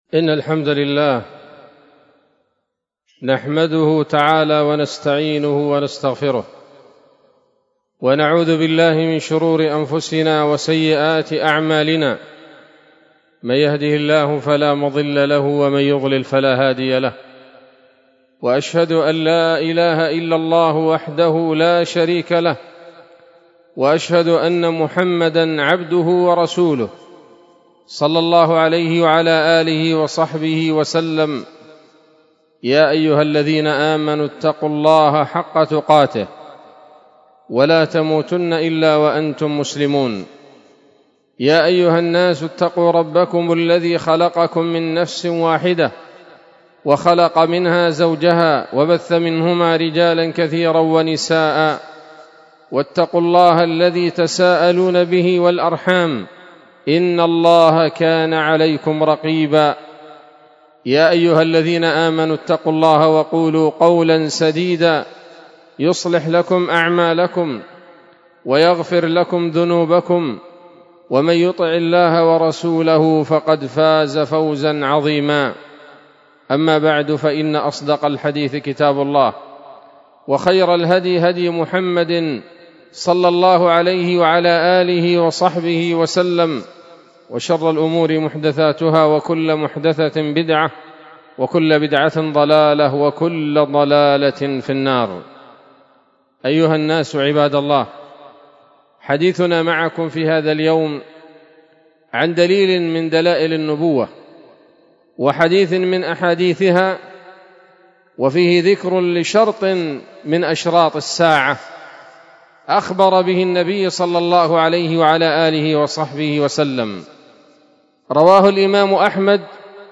خطبة جمعة بعنوان
20 جمادى الأولى 1446 هـ، دار الحديث السلفية بصلاح الدين